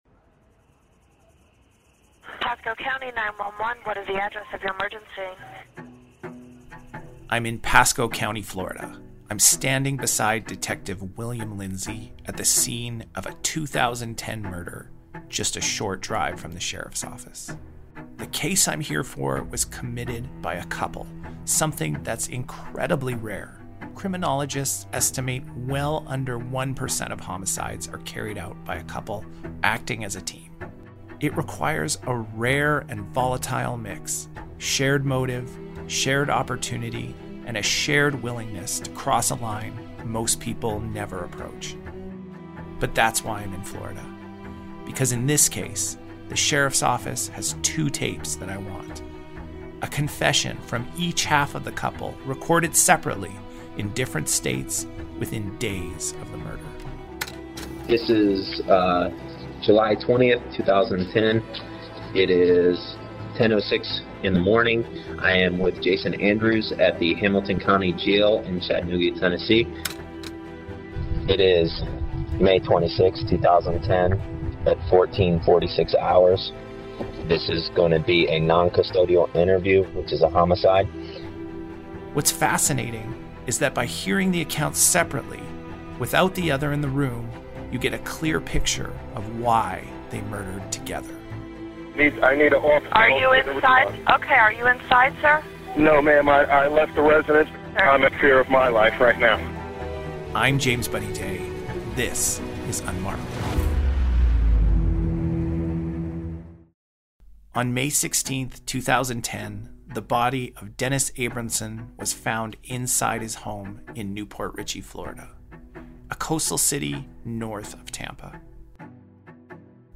Using rare audio recordings and court records, this episode explores the prisoner’s dilemma at its most human: when survival demands betrayal, but loyalty feels safer than freedom.